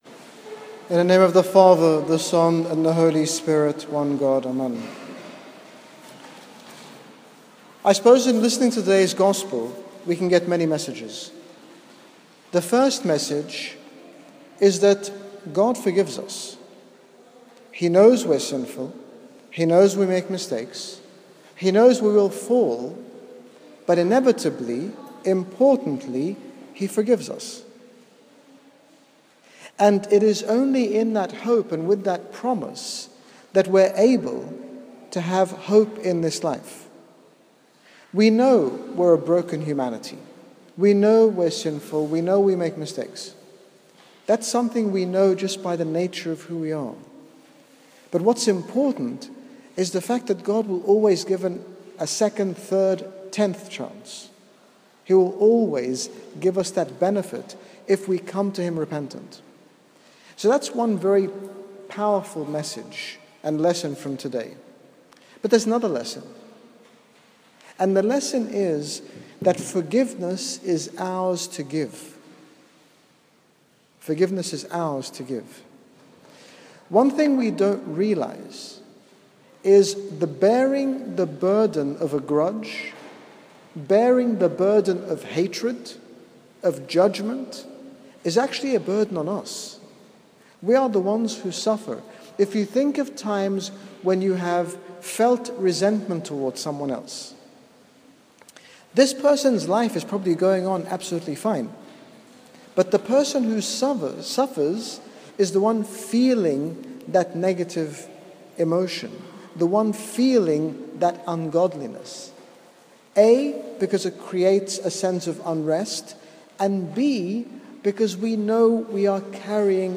In this short sermon at St Paul Ministry, His Grace Bishop Angaelos speaks about the forgiveness of God and how we are liberated through forgiving others.